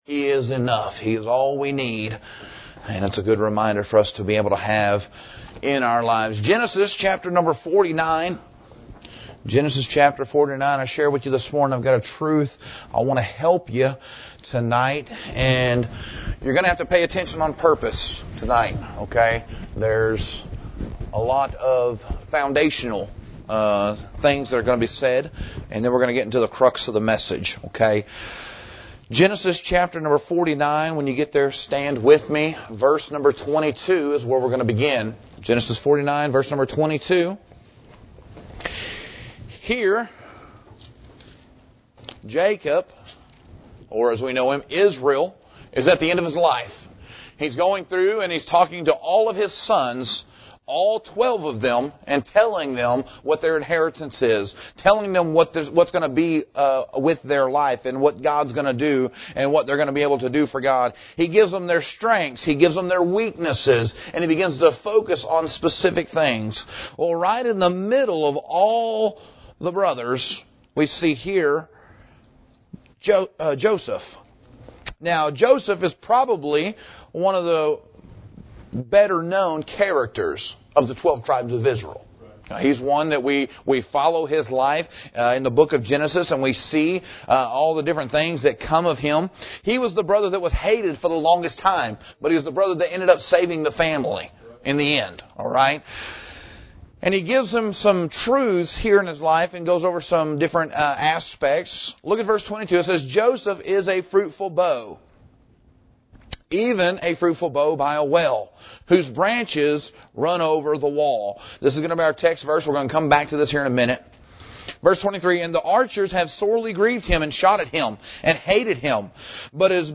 In the first 2 parts of this mini-series we have looked at the Inspiration and Preservation of the Bible. In this sermon